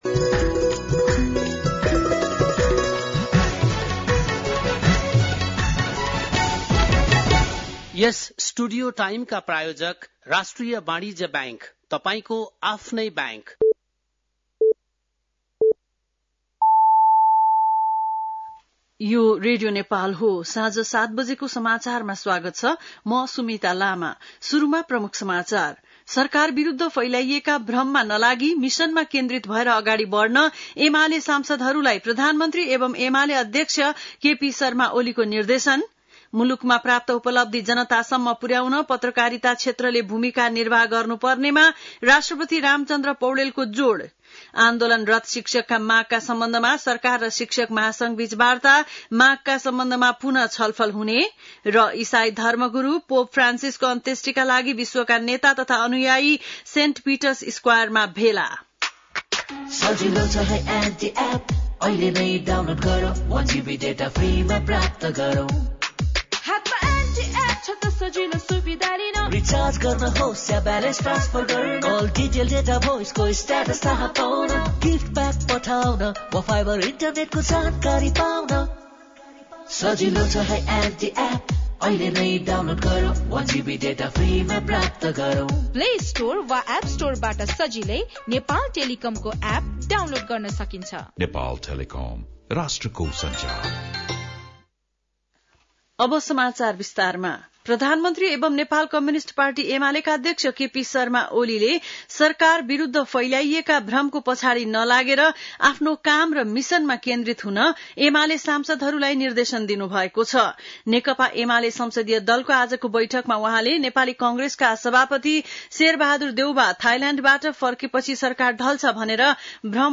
बेलुकी ७ बजेको नेपाली समाचार : १३ वैशाख , २०८२